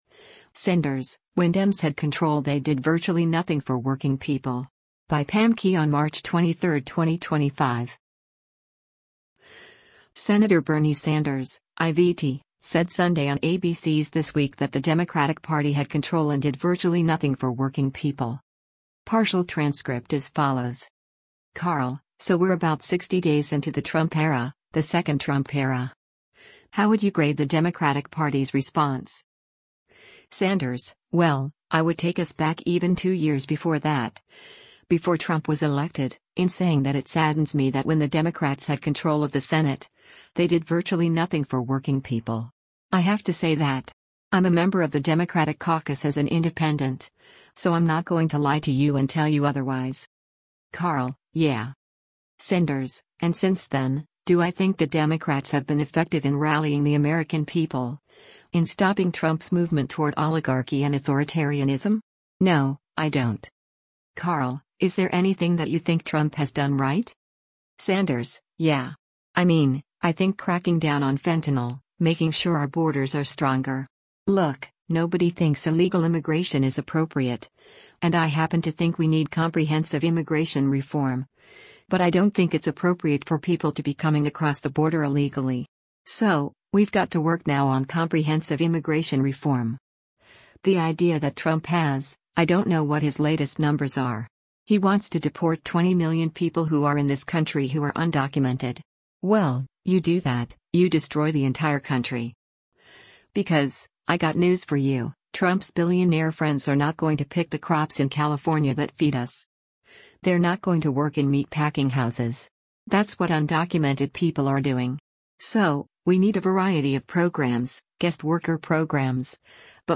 Senator Bernie Sanders (I-VT) said Sunday on ABC’s “This Week” that the Democratic Party had control and “did virtually nothing for working people.”